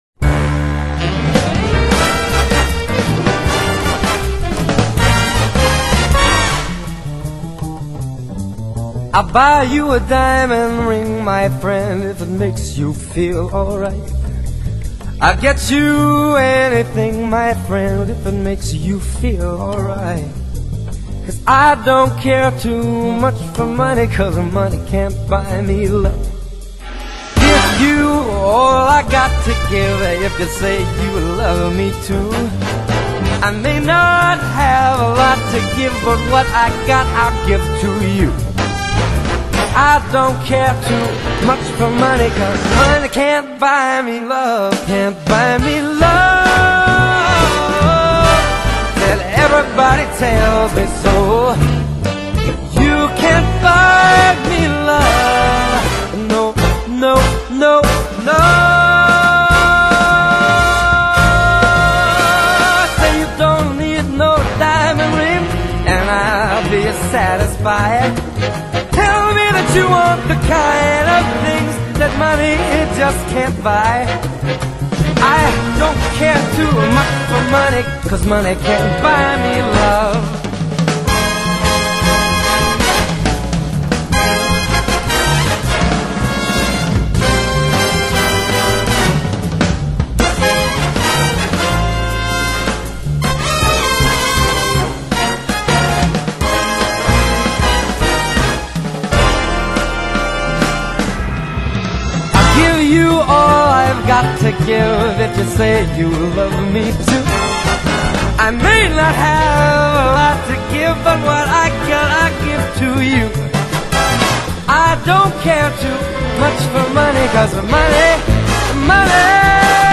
音乐类型：爵士乐